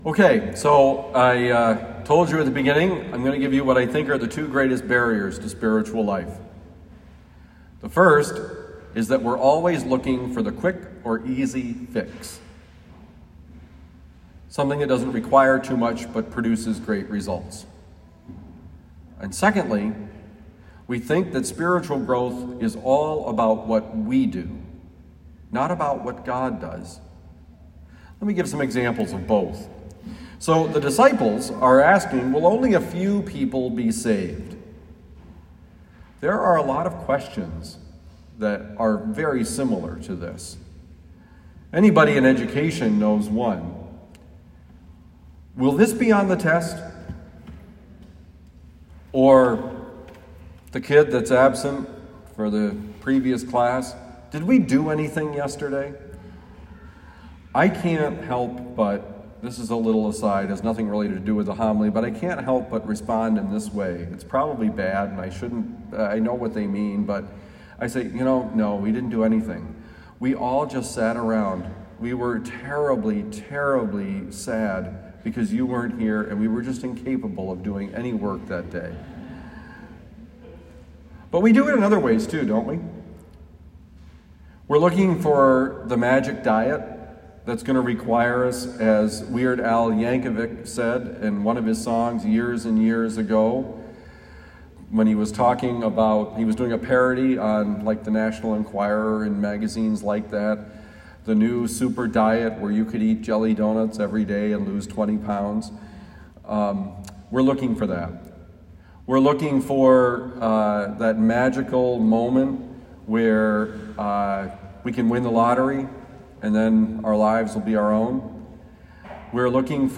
The Danger and the Struggle: It’s Not About Us, It’s About God: Homily for Sunday, August 21, 2022
Given at Our Lady of Lourdes Parish, University City, Missouri.